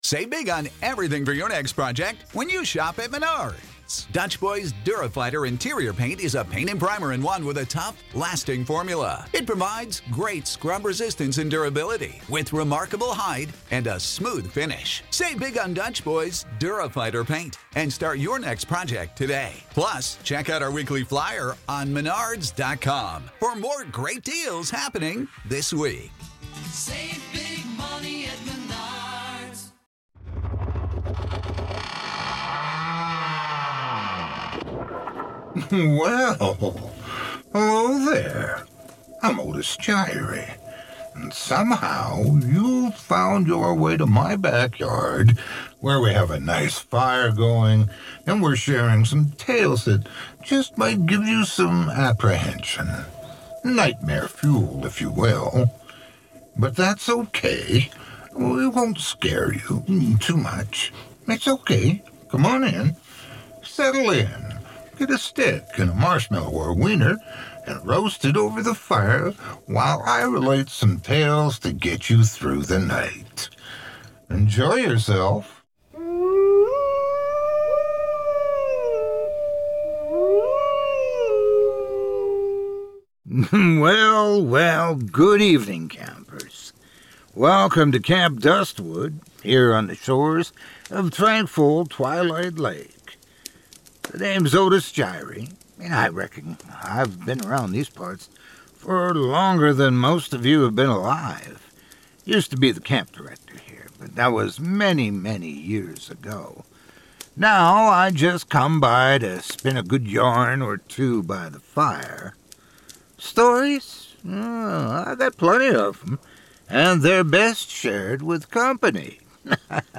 A Horror Anthology and Scary Stories Podcast